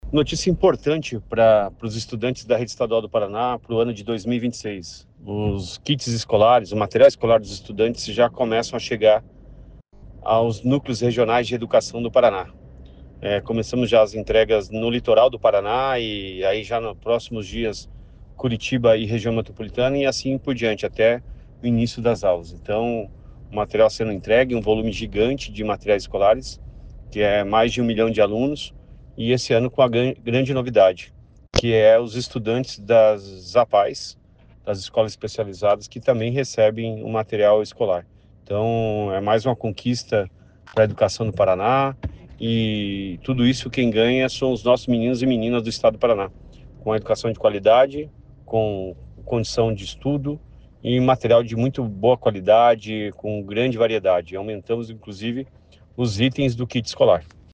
Sonora do secretário da Educação, Roni Miranda, sobre a entrega de kits escolares para o ano letivo de 2026